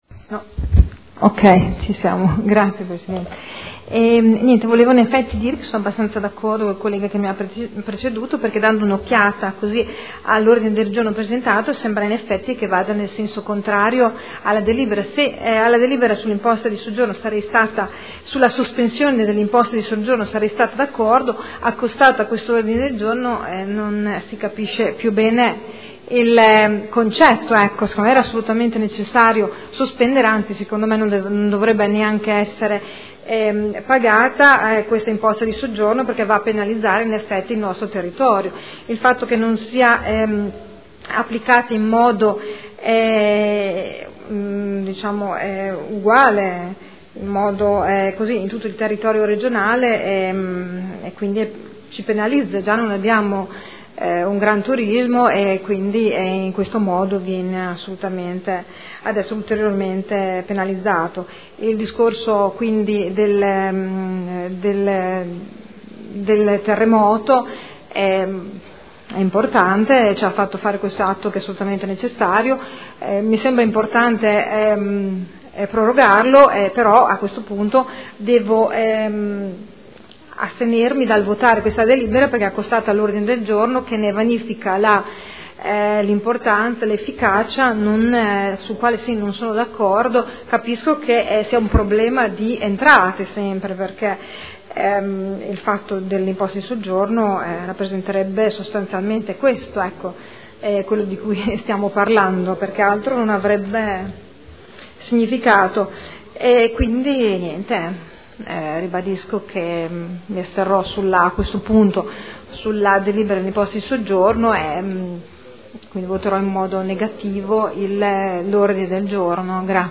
Sandra Poppi — Sito Audio Consiglio Comunale